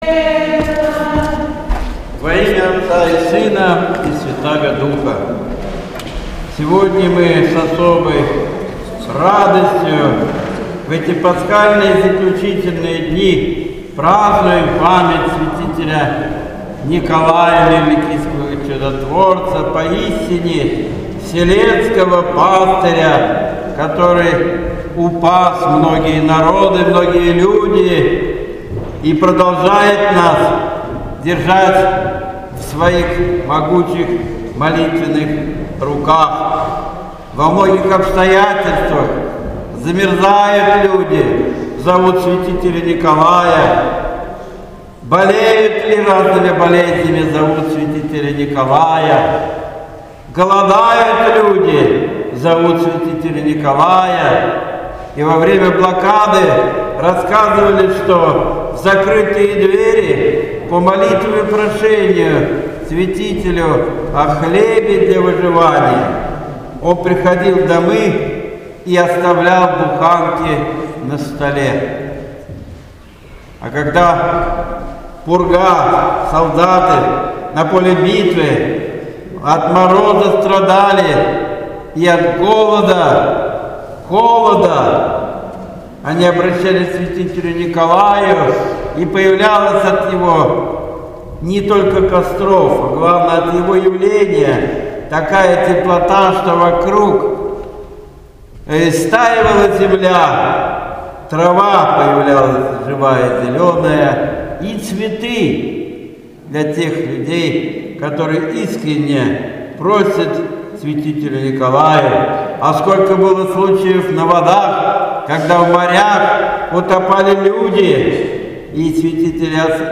Божественная Литургия 22 мая 2017 года